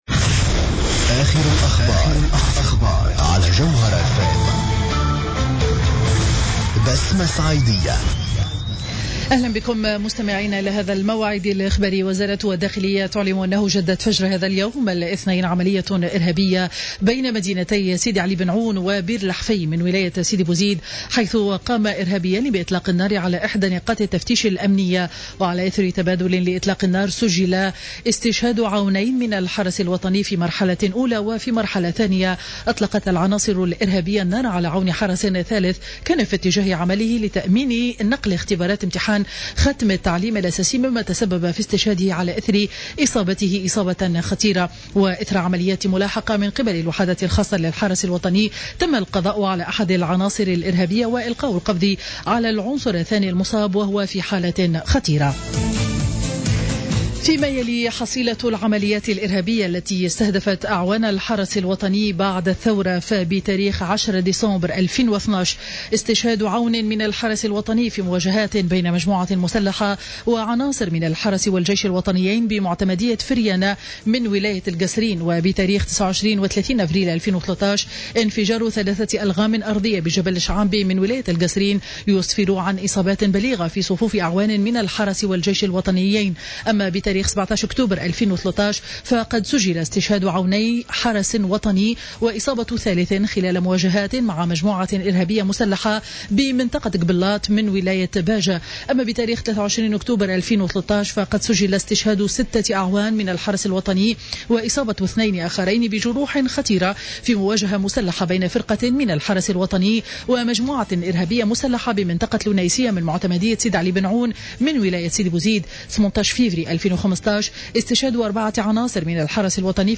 نشرة أخبار منتصف النهار ليوم الاثنين 15 جوان 2015